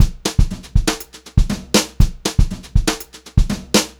120HRBEAT3-R.wav